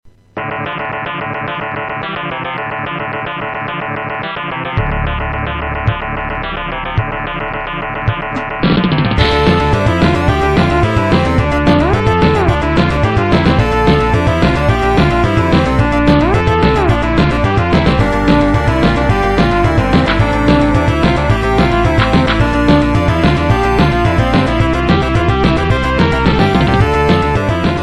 It has sampled drums which an OPLL cannot do.
It could also be for, perhaps, the PC-9801-86 sound board which also matches the combination of FM, PSG, and samples heard here.